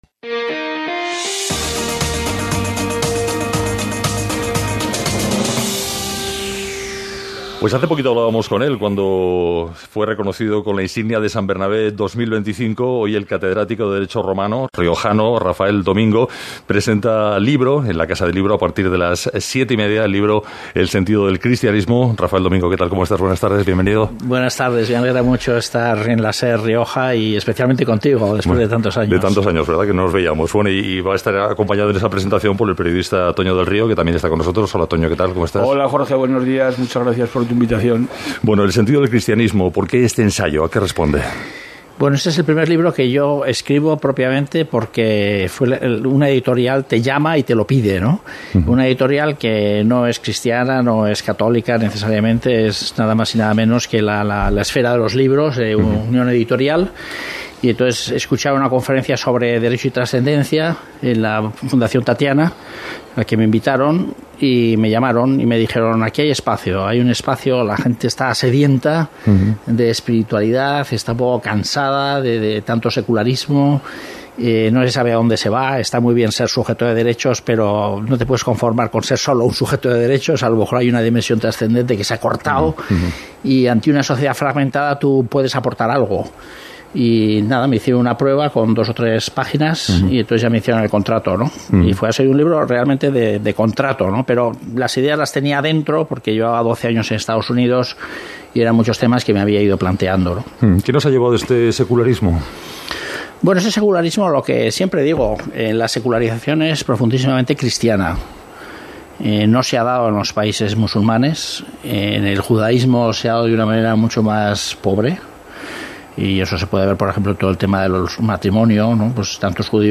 Entrevista en SER La Rioja